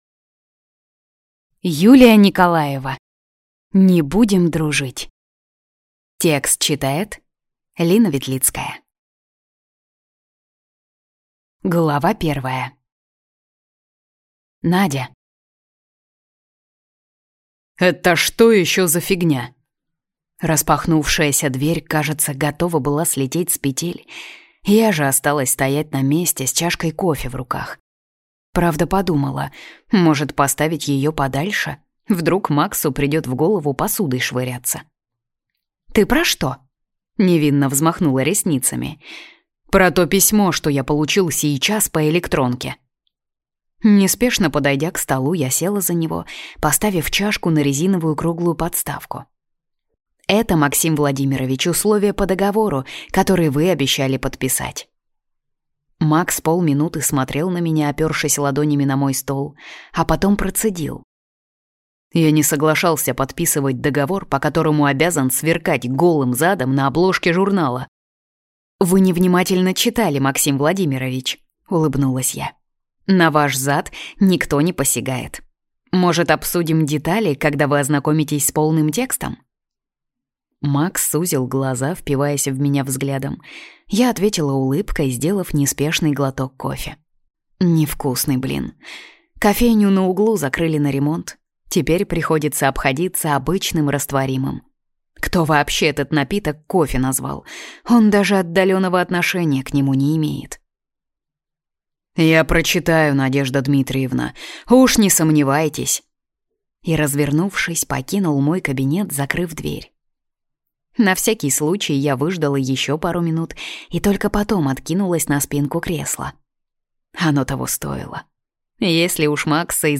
Аудиокнига Не будем дружить | Библиотека аудиокниг